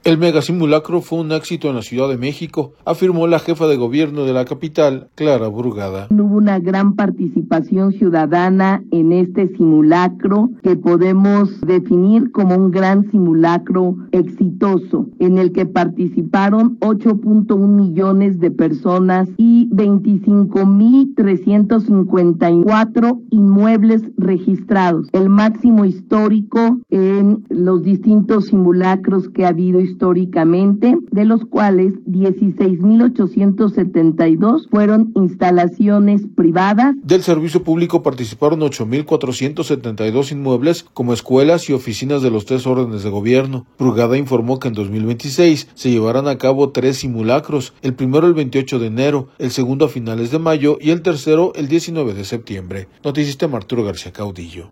audio El Mega Simulacro fue un éxito en la Ciudad de México, afirmó la Jefa de Gobierno de la capital, Clara Brugada.